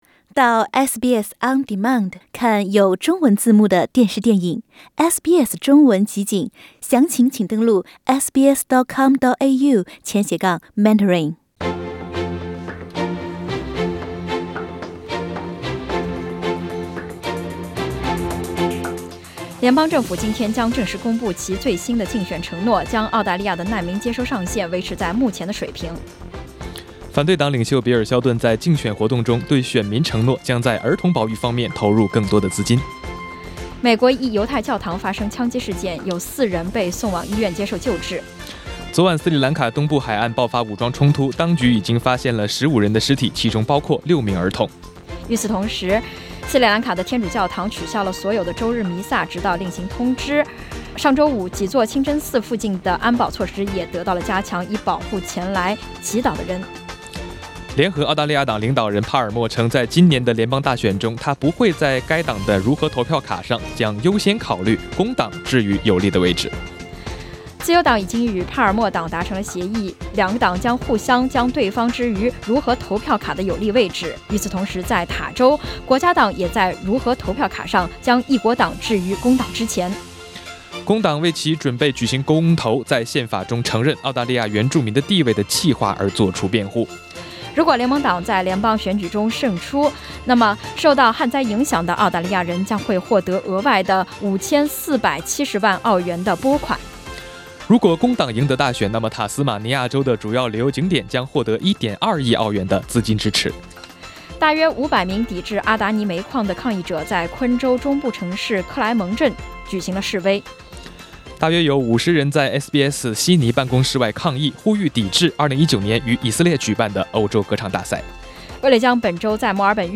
SBS 早新闻 （4月28日）